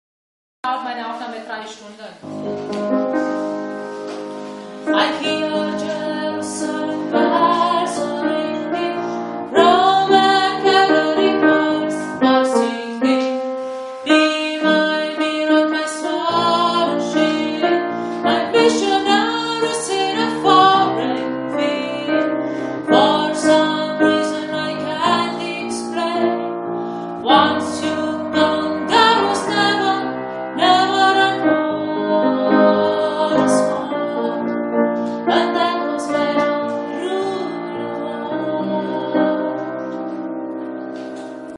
Viva la Vida – Alto-Refrain